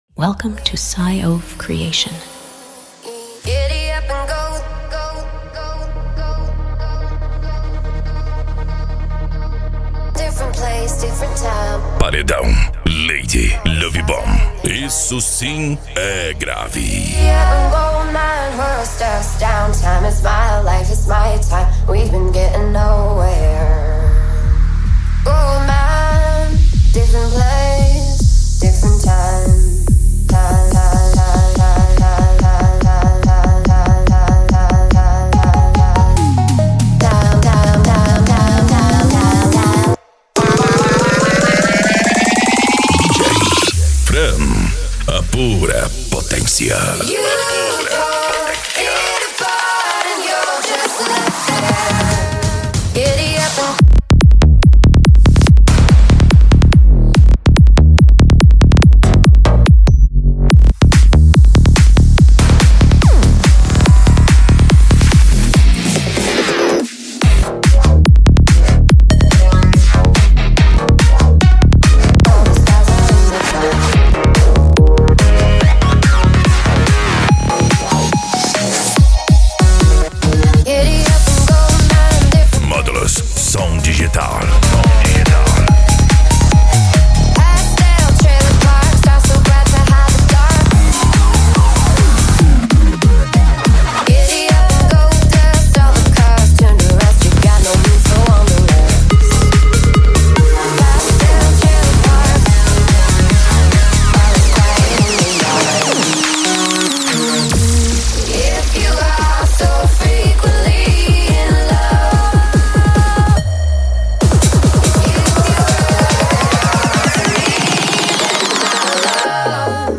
Bass
Deep House
Eletronica
Psy Trance